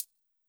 CLF Closed Hat 2.wav